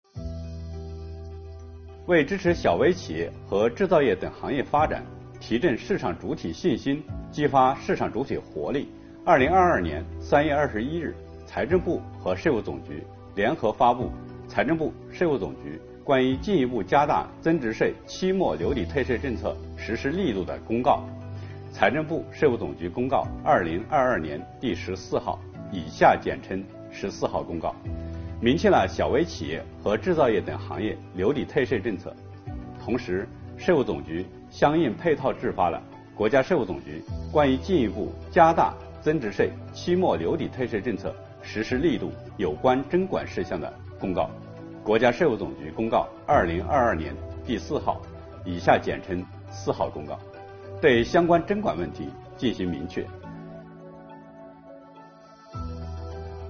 国家税务总局货物和劳务税司副司长刘运毛担任主讲人，详细解读了有关2022年大规模留抵退税政策的重点内容以及纳税人关心的热点问题。